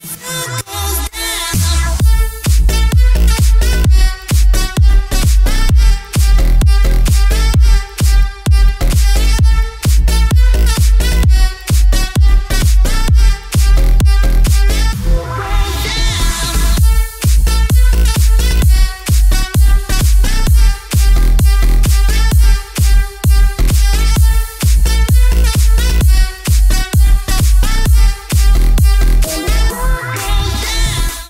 remix
EDM
future house
Bass House
энергичные
Зажигательный рингтон на звонок